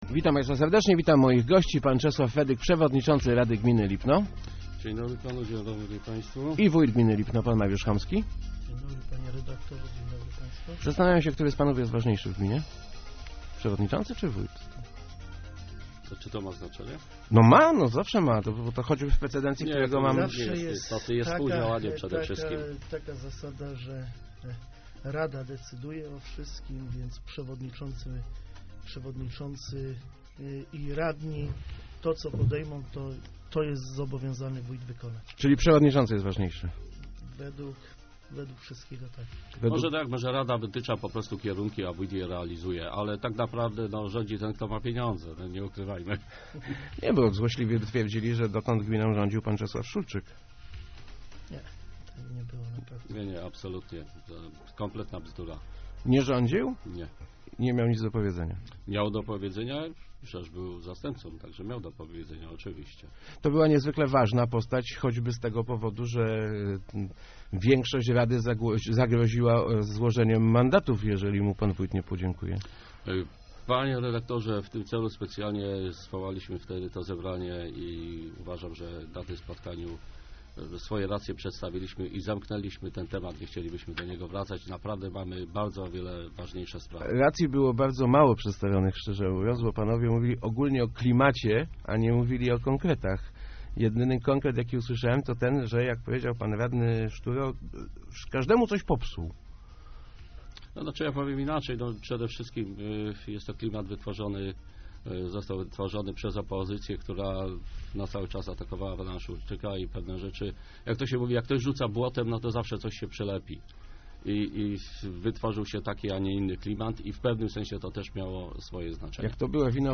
Inicjatorzy refendum to ludzie, którzy stracili uk�ady, przywileje i dochodzy z gminy - mówili w Rozmowach Elki wójt Lipna Mariusz Homski i przewodnicz�cy Rady Gminy Czes�aw Fedyk. Ich zdaniem zarzuty opozycji nie maj� �adnego uzasadnienia.